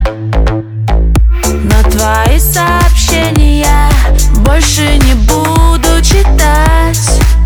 женский голос
попса